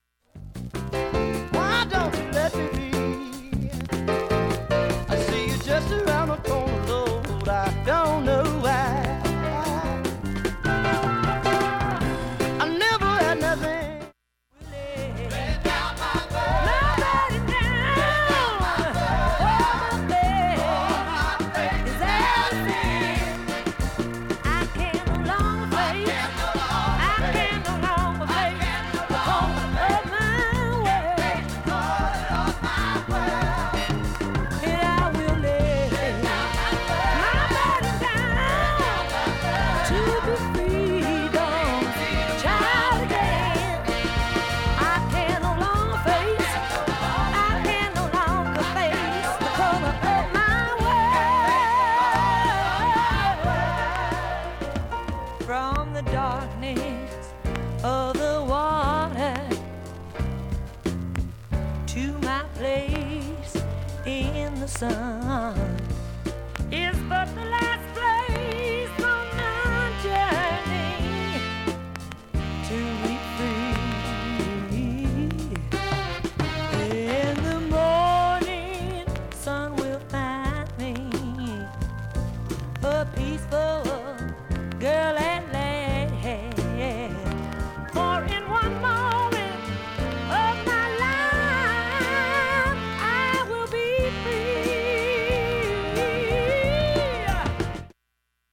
普通に聴けます音質良好全曲試聴済み。
A-1序盤にかすかなプツが５回出ます。
単発のかすかなプツが８箇所